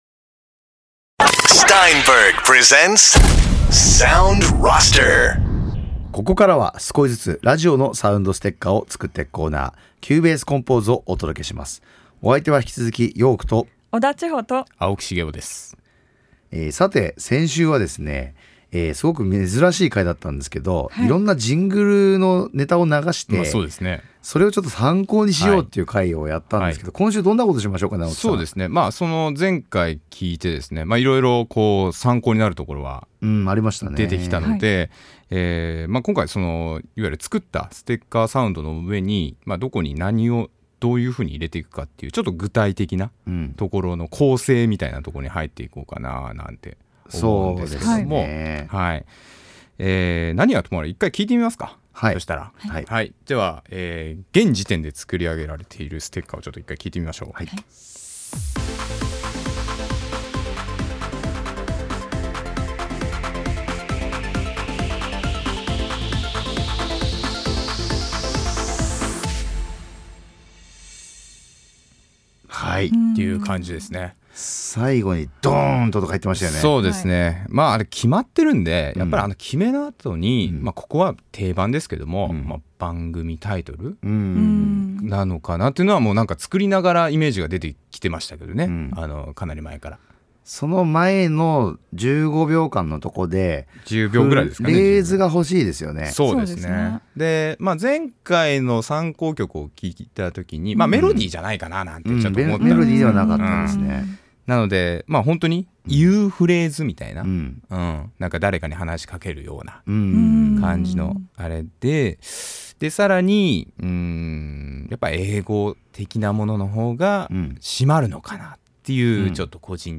Steinberg が提供するラジオ番組「Sound Roster」。